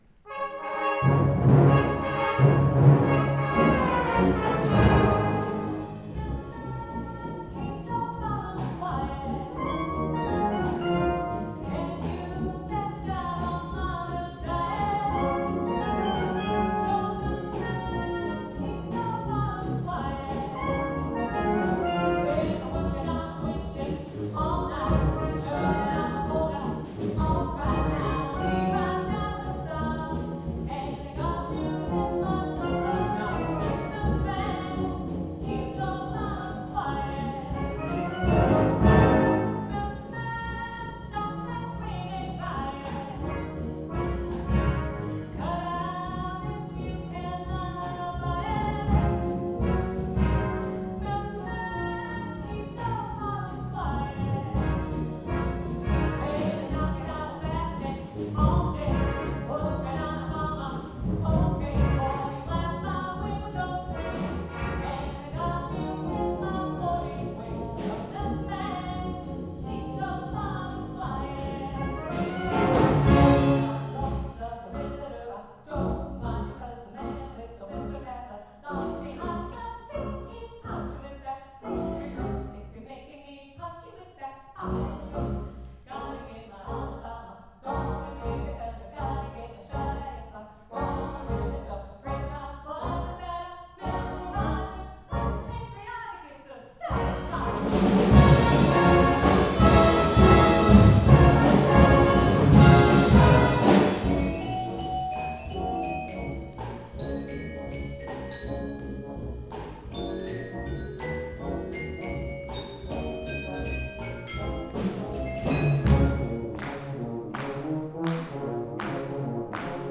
Performance #275 – Saturday, October 7, 2023 – 7:30 PM
Zion Lutheran Church, Anoka, MN